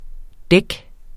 Udtale [ ˈdεg ]